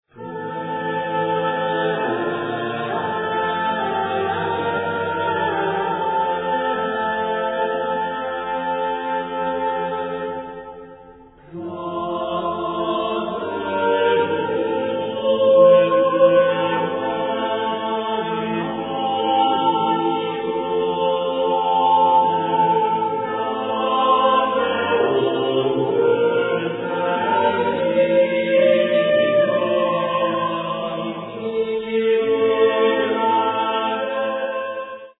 Renaissance Polyphony